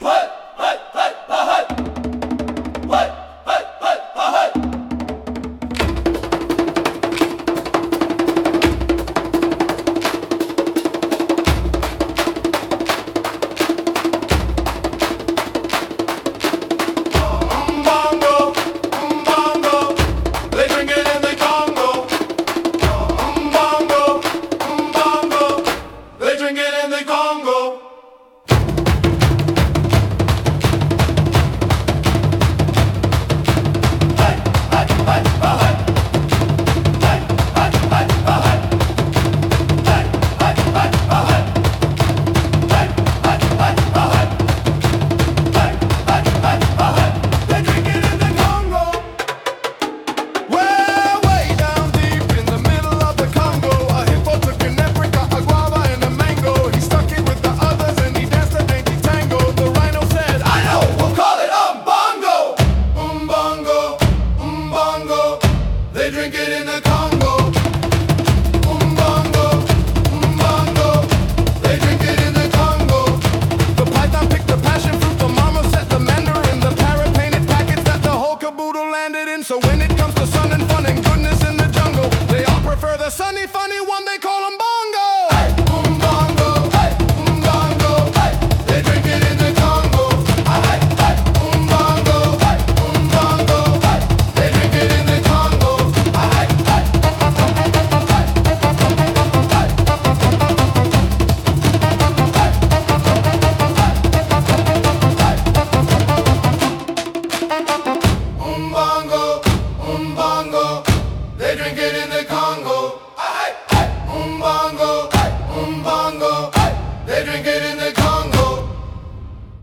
And here's a slightly more "normal" version.